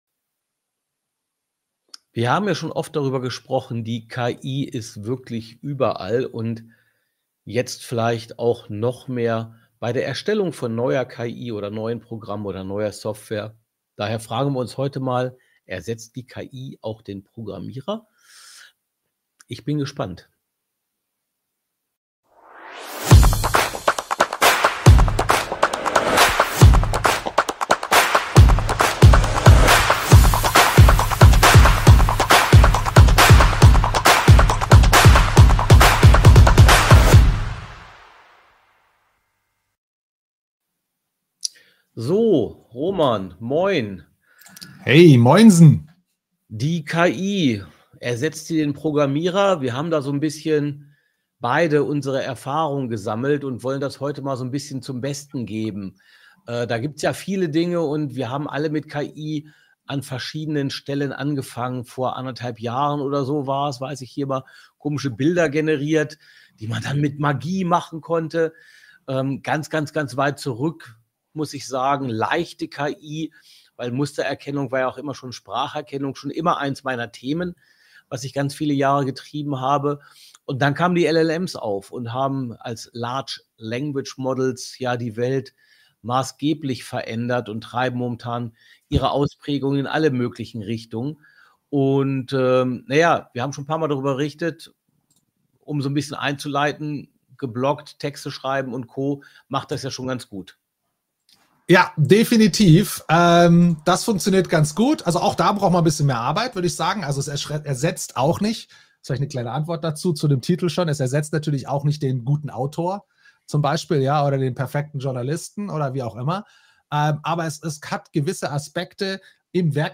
Freut Euch auf viele praktische Insights - wie immer im LiveStream am Mittwoch, pünktlich um 12:29 Uhr.